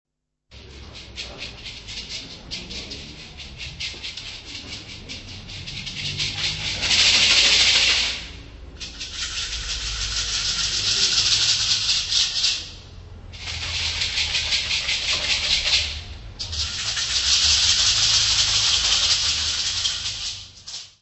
Carnavals du Bresil : Rio, Recife, Bahia : live recording = Carnivals of Brazil
Área:  Tradições Nacionais
Makulele - Danse - Bahia.